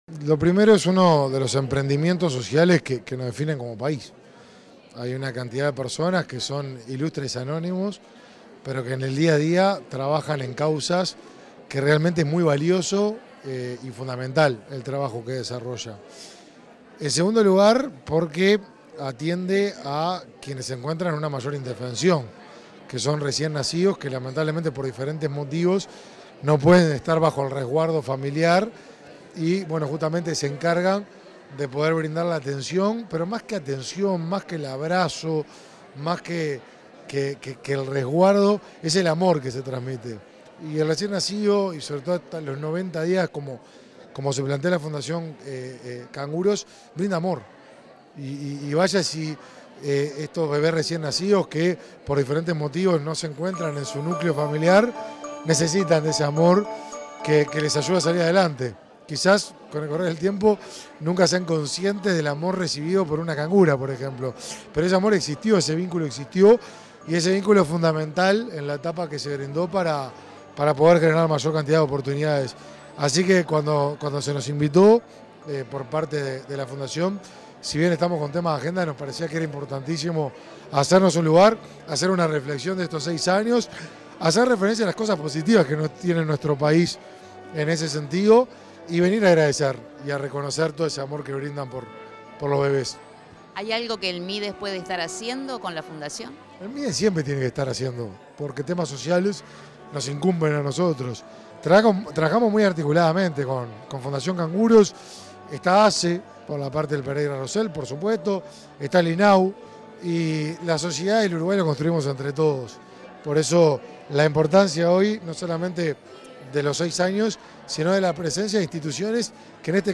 Declaraciones del ministro de Desarrollo Social, Martín Lema
Tras el evento, el ministro Lema efectuó declaraciones a Comunicación Presidencial.